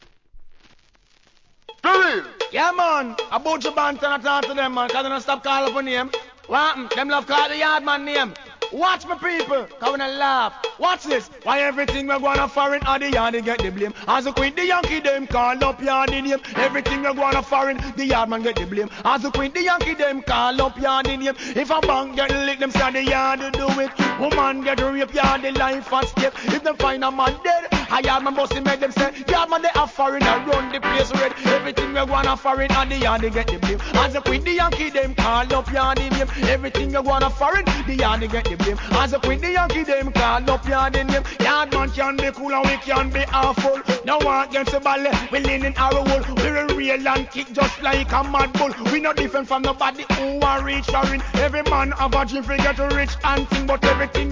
REGGAE
1991年、当時の王道な軽快RHYTHM!!